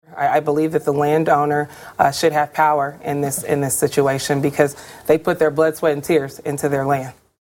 abortion and carbon pipelines during their debate last night on Iowa PBS.